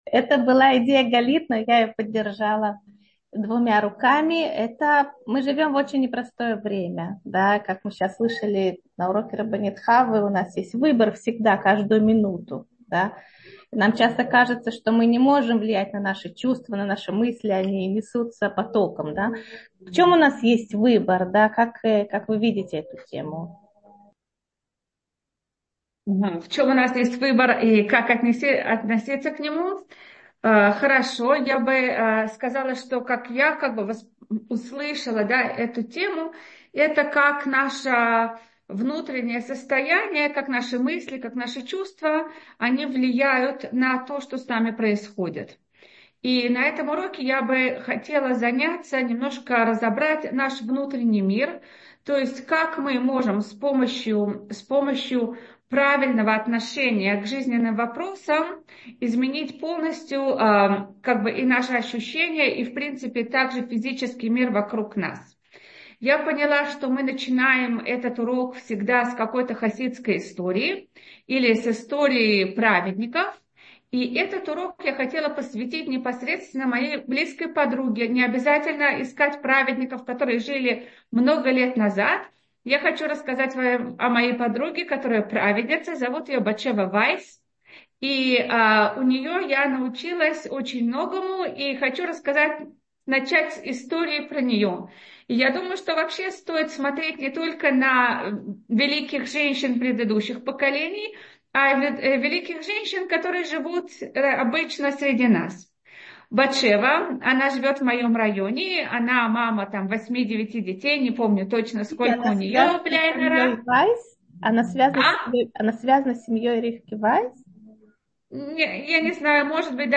Утренний зум в гостях у Толдот. Почему после хороших поступков часто так плохо? Насколько реально ли изменить свое происхождение? Может ли проклятый стать благословенным?